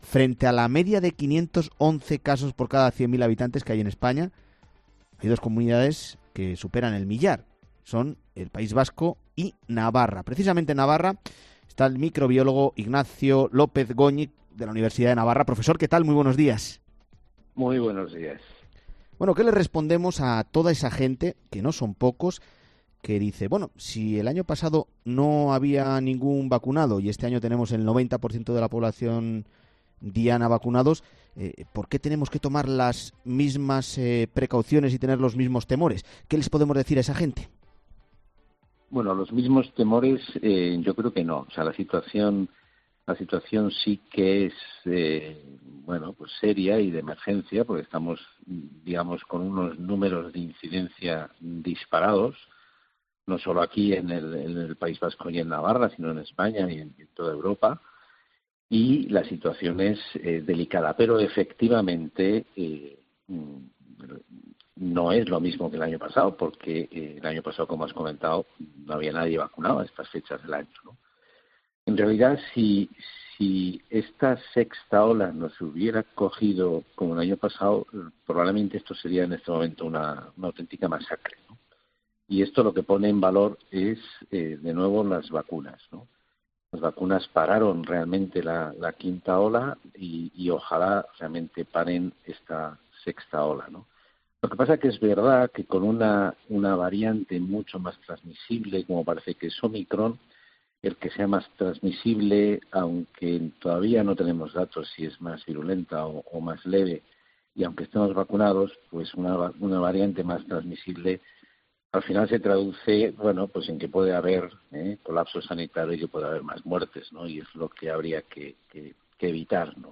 quien ha pasado por los micrófonos de “La Mañana del Fin de Semana” de COPE para comentar el incremento de casos en el país.